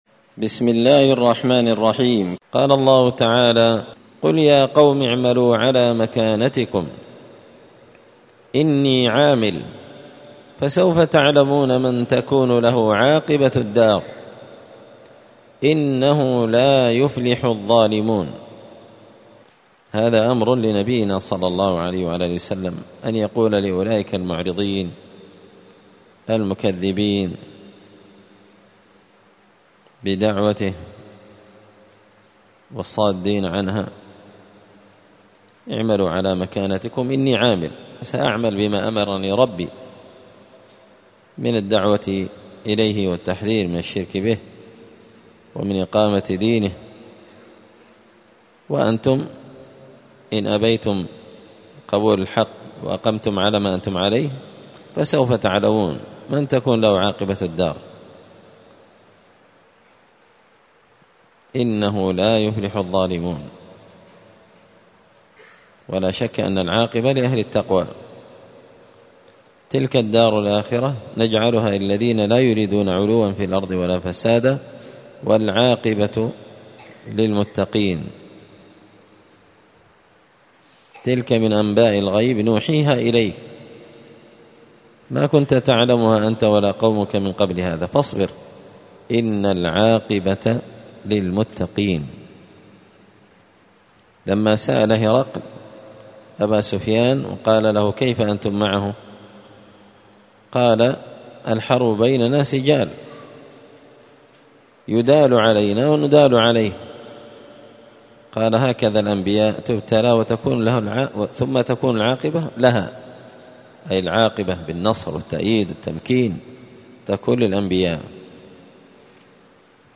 مختصر تفسير الإمام البغوي رحمه الله الدرس 357